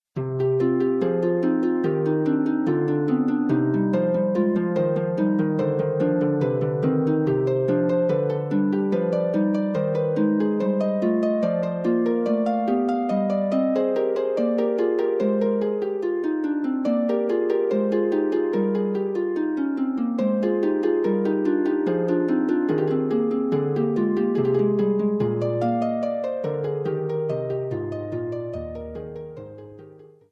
for solo pedal harp.